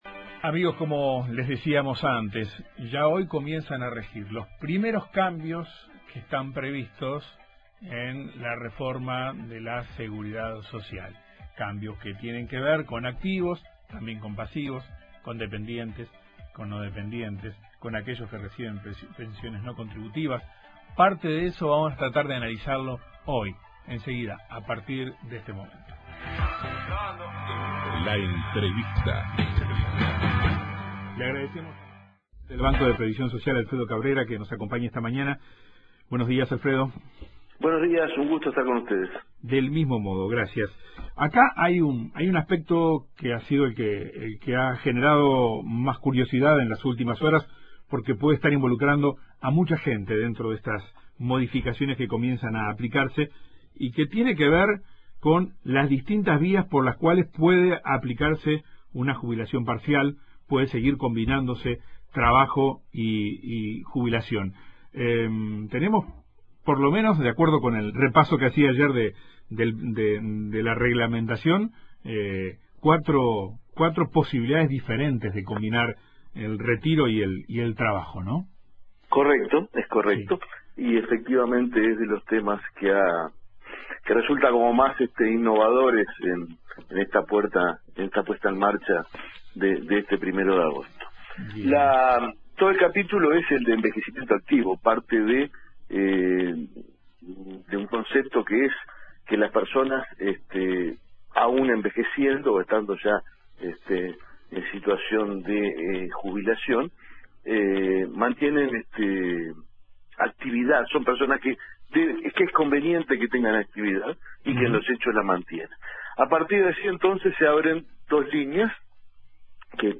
El presidente del Banco de Previsión Social (BPS) Alfredo Cabrera, explicó en Informativo Uruguay que el nuevo sistema ofrece la posibilidad de trabajar para quienes ya se jubilaron, pero también para aquellos activos que están en edad de jubilarse y se disponen a combinar el retiro con trabajo, sean dependientes o no.